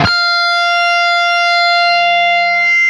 LEAD F 4 CUT.wav